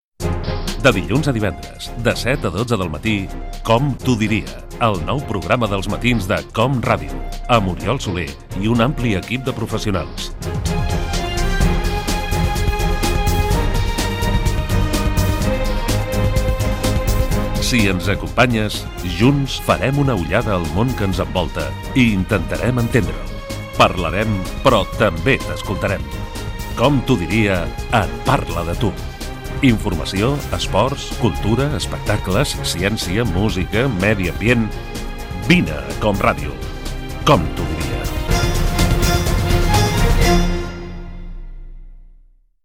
Promoció del programa
Info-entreteniment